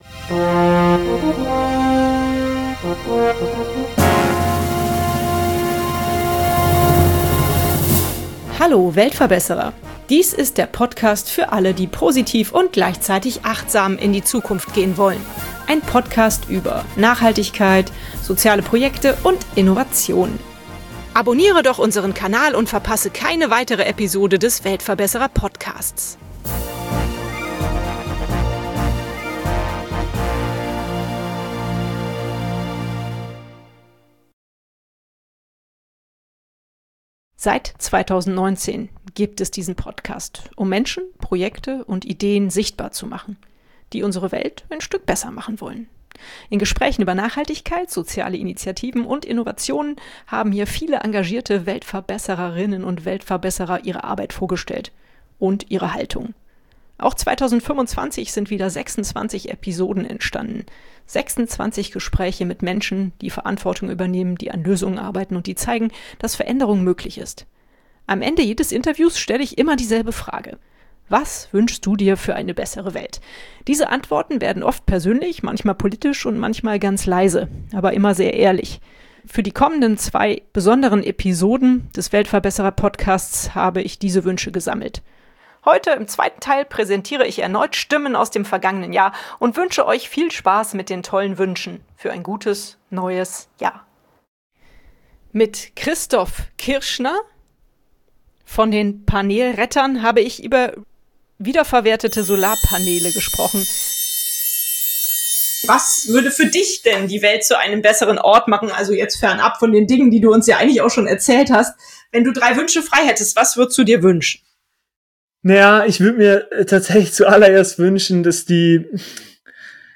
Stimmen aus dem Podcastjahr 2025 – ehrlich, nachdenklich, hoffnungsvoll.
Diese Folge ist kein klassisches Interview, sondern eine Collage aus Gedanken, Hoffnungen und Visionen.